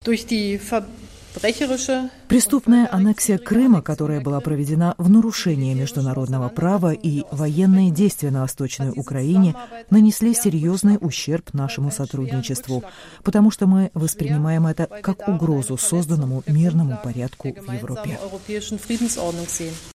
На пресс-конференции после переговоров Путин пообещал оказать влияние на ДНР и ЛНР, а Меркель заявила, что, несмотря на разногласия, Германия работает вместе с Россией, а не против нее